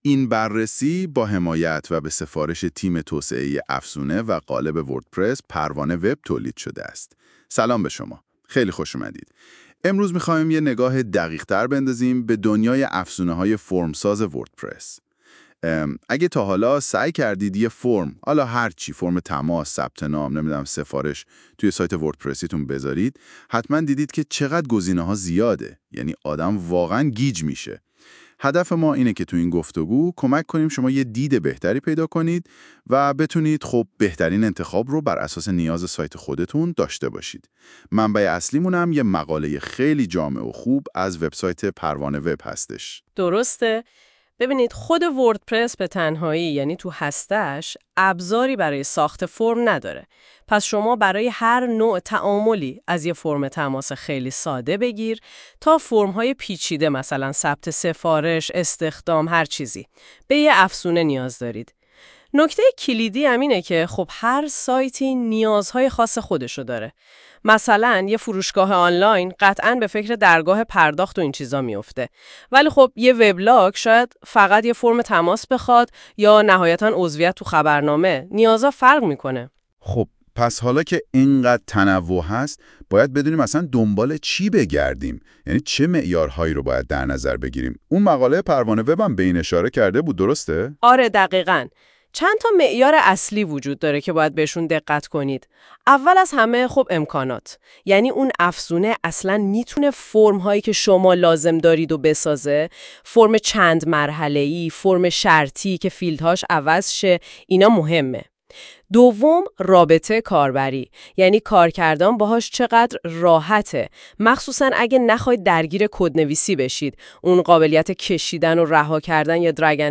قبل از شروع مقاله ، اگه حوصله یا فرصت مطالعه این مقاله رو ندارید ، پیشنهاد می کنیم پادکست صوتی زیر که با ابزار هوش مصنوعی (گوگل notebooklm ) به زبان فارسی توسط تیم پروان وب تولید شده است ، گوش کنید.